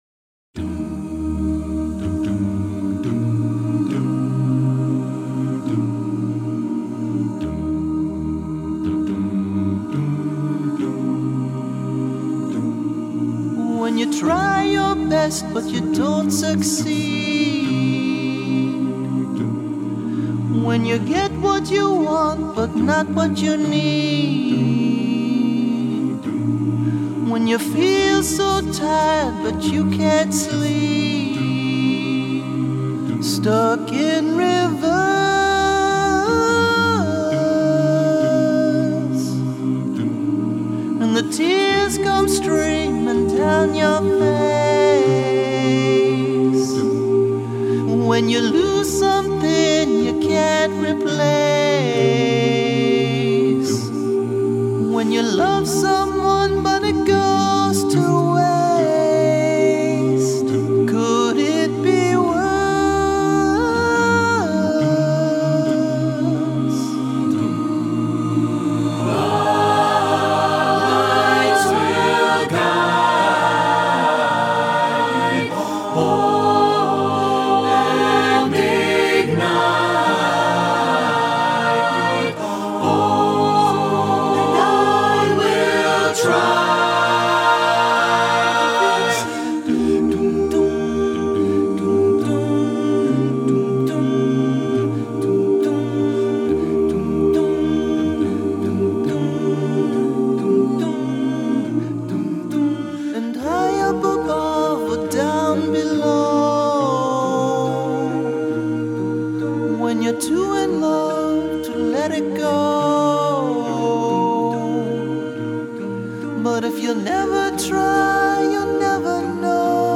Voicing: SATTBB a cappella